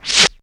SWOOSH.wav